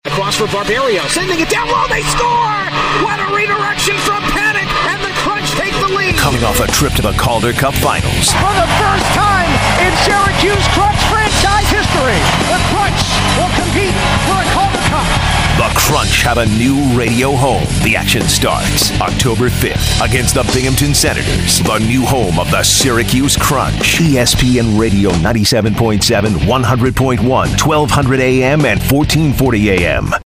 ESPN Radio promo